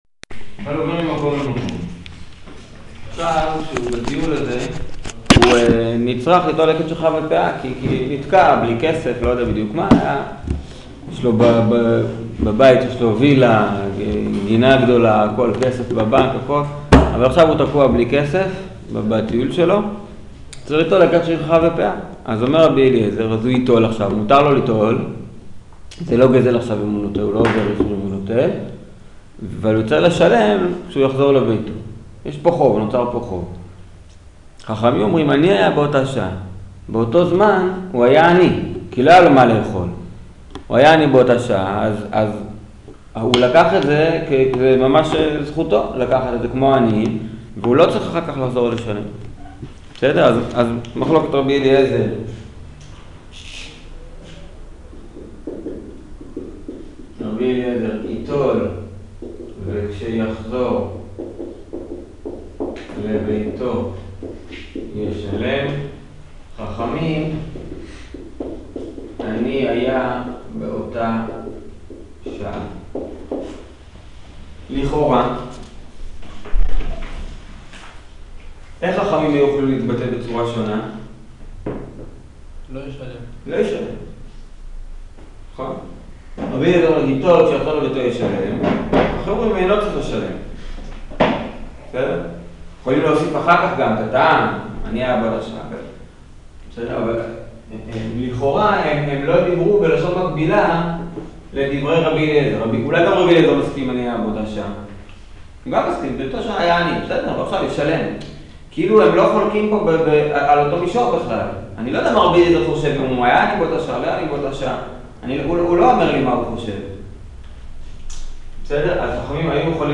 שיעור מחלוקת שאינה סימטרית